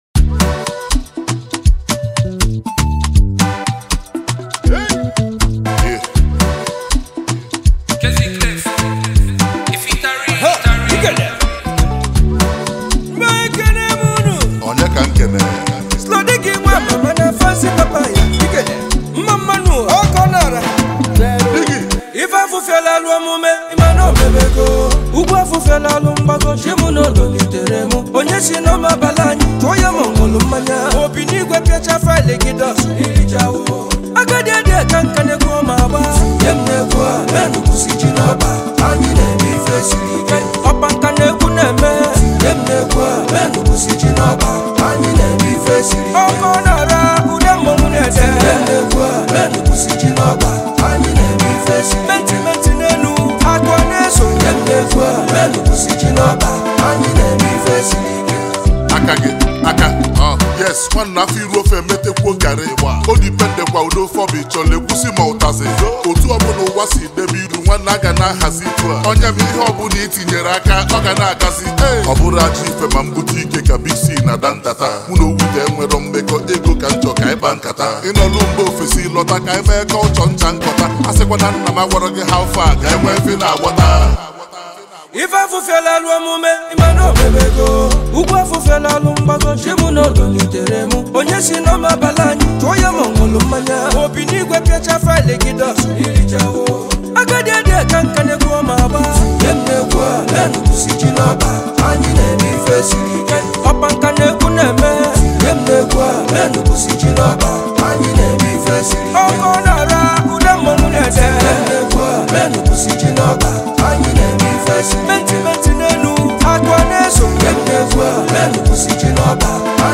Nigerian indigenous rap sensation and songwriter